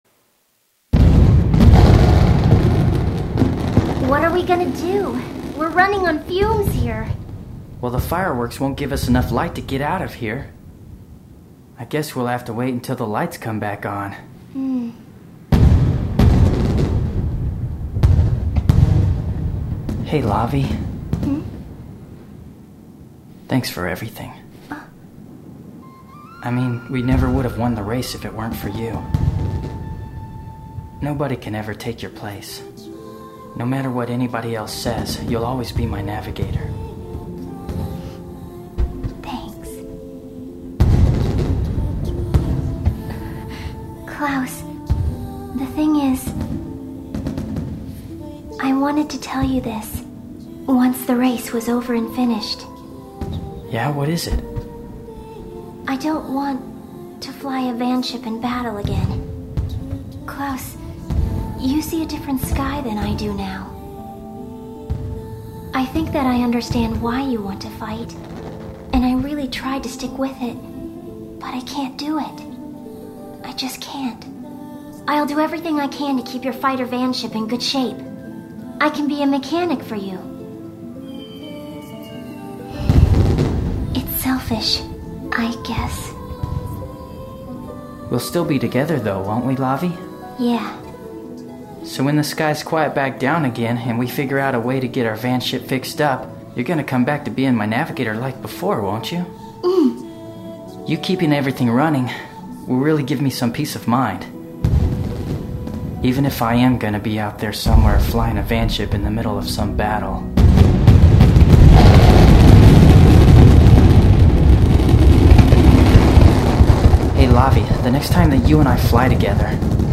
うれしいので、発表された声優さんが他の作品で演じた声を聴きながらどんな感じになるのか想像してみました。